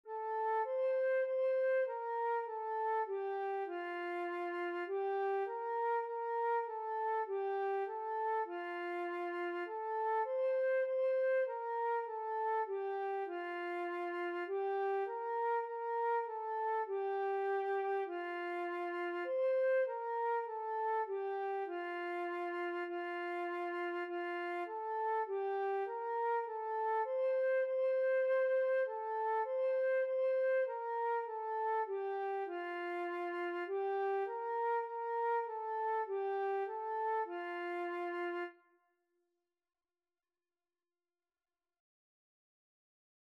4/4 (View more 4/4 Music)
F5-C6
Beginners Level: Recommended for Beginners
Flute  (View more Beginners Flute Music)
Classical (View more Classical Flute Music)